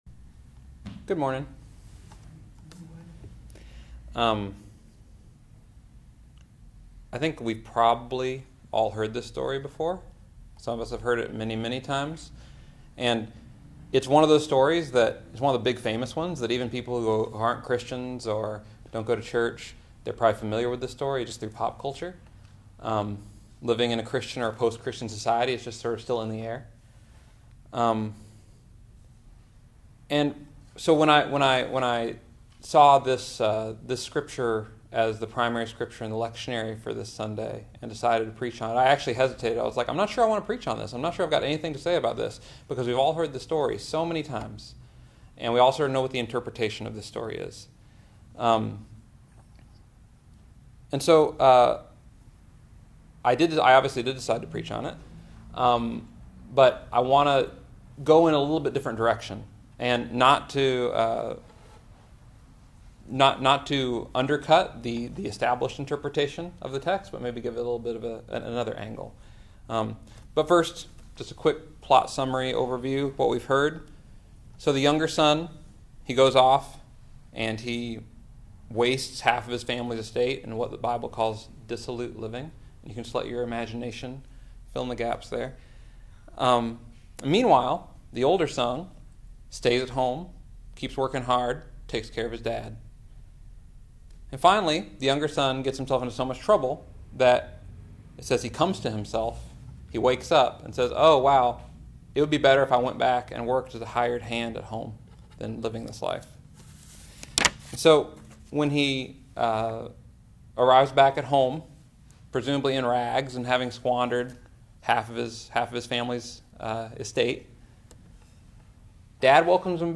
BFC-Sermon-3.27.22-converted.mp3